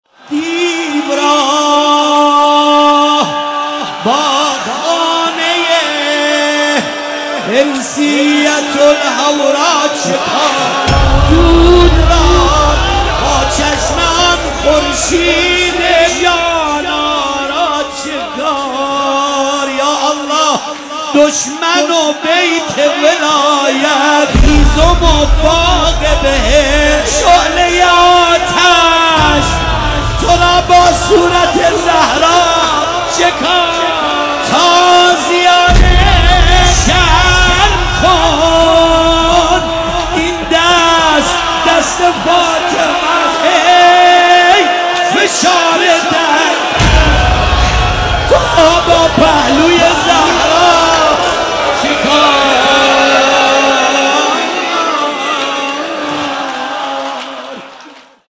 مداحی
محفل عزاداران حضرت زهرا (س) شاهرود